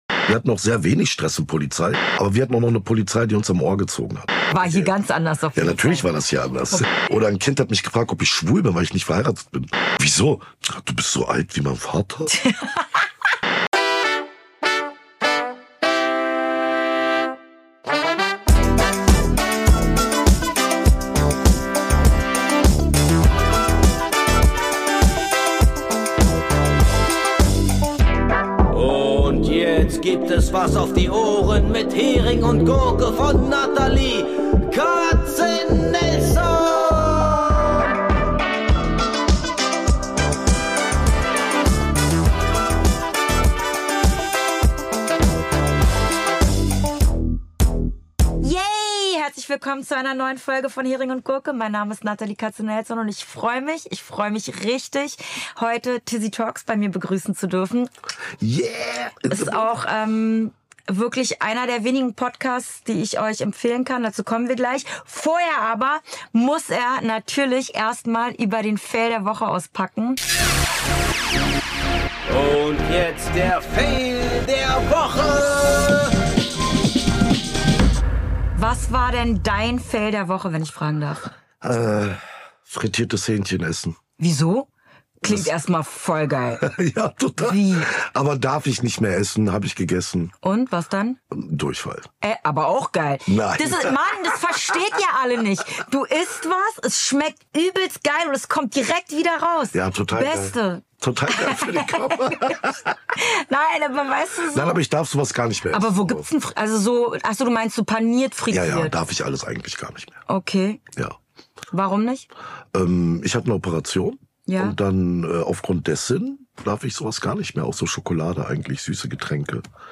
Ein Podcaster als Gast.
Ehrlich, direkt und herrlich unvorbereitet.